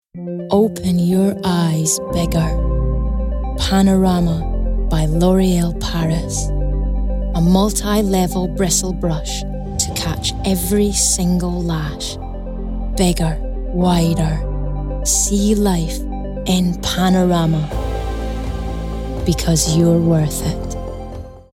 Scottish
Female
Friendly
Warm